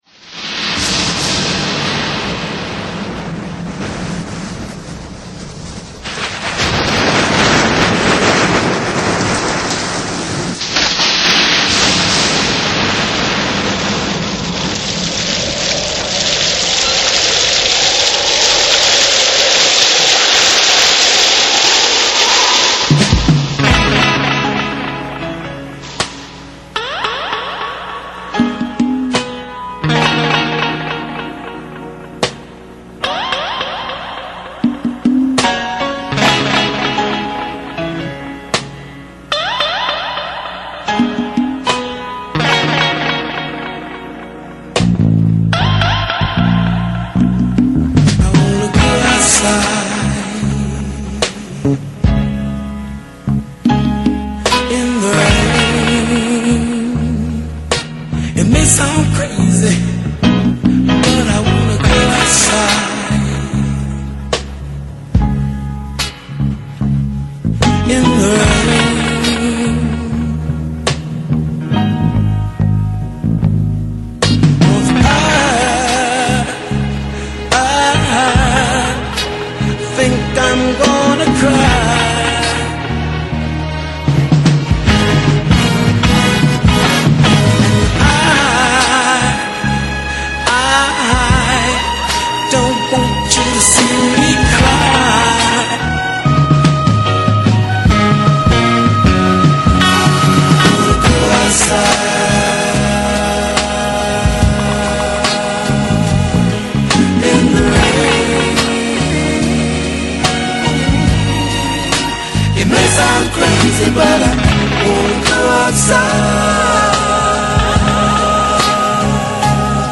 Romanticas